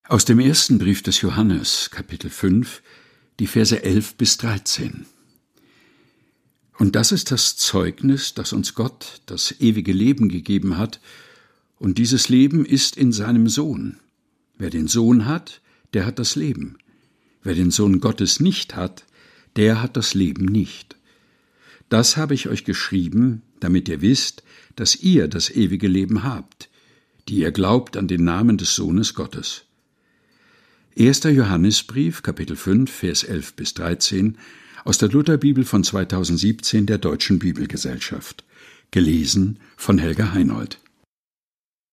Ohrenweide ist der tägliche Podcast mit Geschichten, Gebeten und Gedichten zum Mutmachen und Nachdenken - ausgesucht und im heimischen Studio vorgelesen